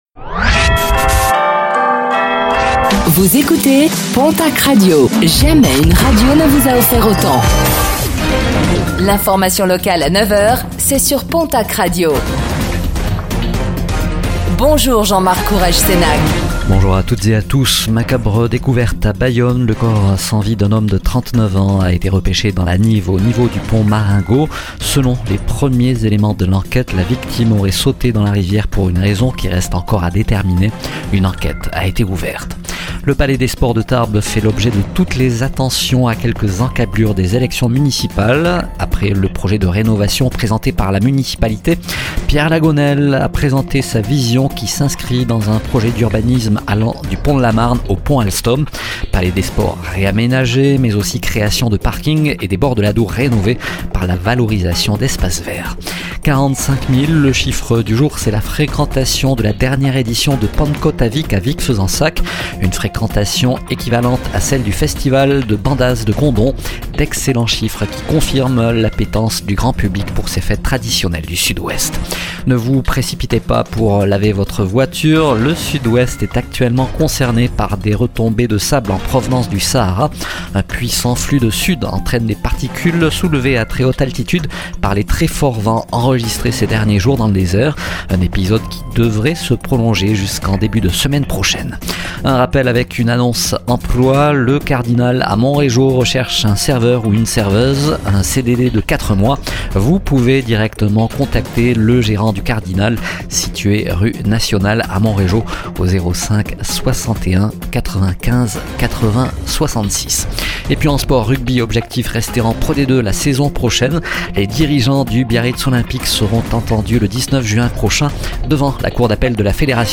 Réécoutez le flash d'information locale de ce jeudi 12 juin 2025